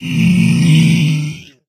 Minecraft Version Minecraft Version latest Latest Release | Latest Snapshot latest / assets / minecraft / sounds / mob / zombified_piglin / zpigdeath.ogg Compare With Compare With Latest Release | Latest Snapshot
zpigdeath.ogg